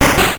Warp_Door.wav